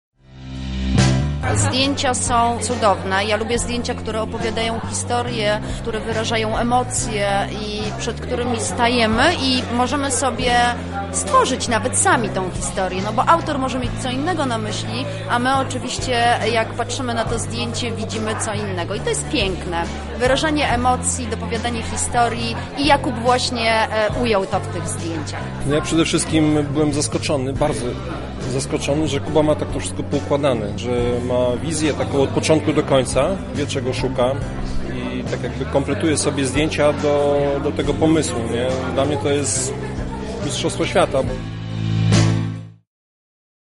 Nasz reporter pytał uczestników o wrażenia po obejrzeniu wystawy.